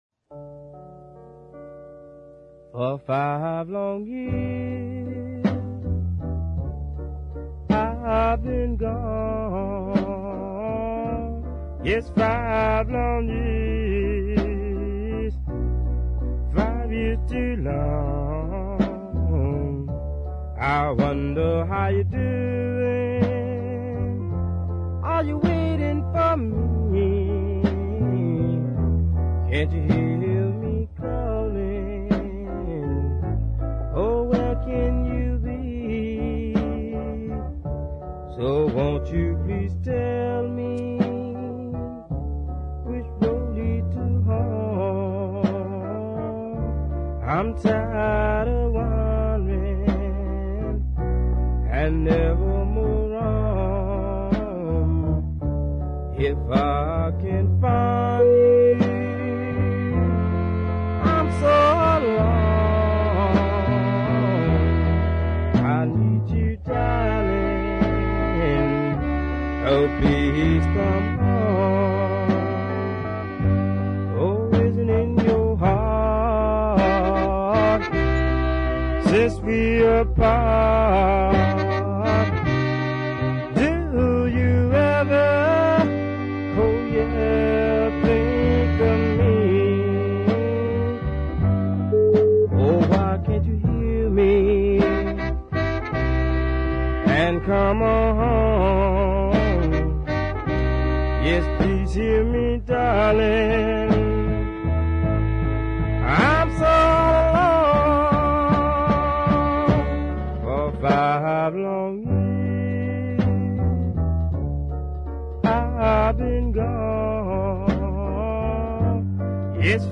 with its plodding rhythm and gentle arrangement